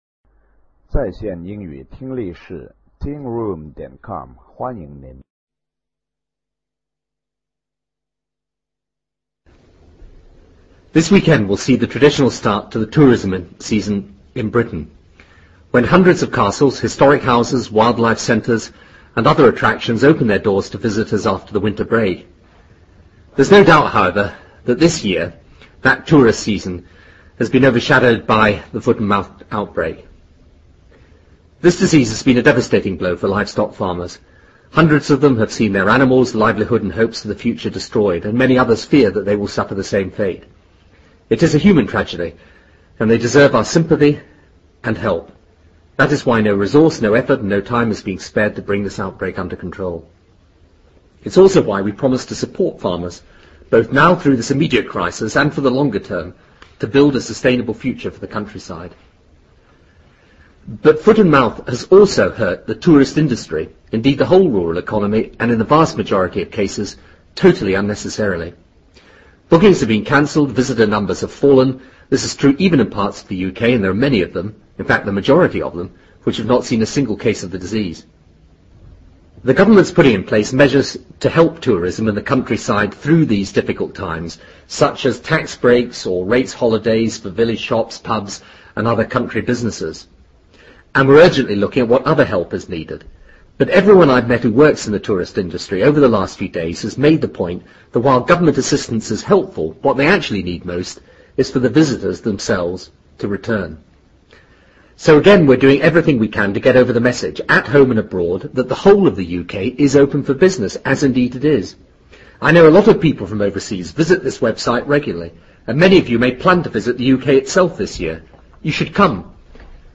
布莱尔首相演讲:Tourism and foot and mouth disease 听力文件下载—在线英语听力室